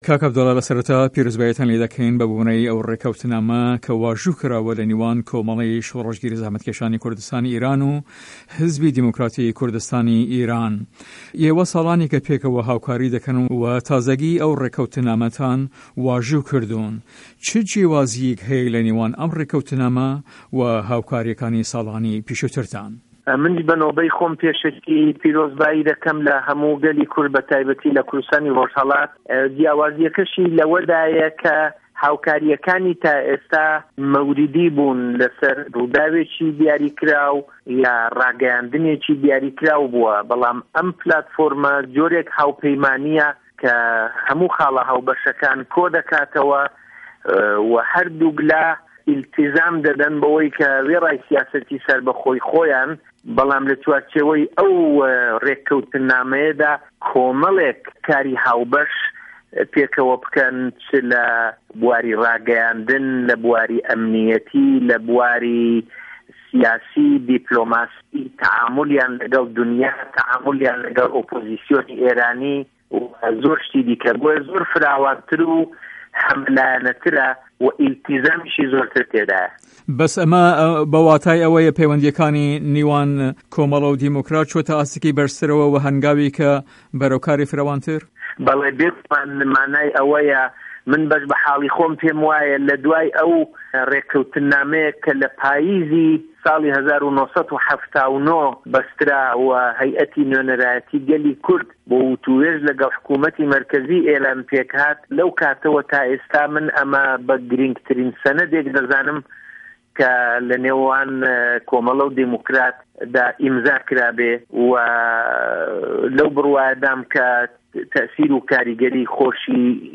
وتو وێژ له‌گه‌ڵ عه‌بدوڵا موهته‌دی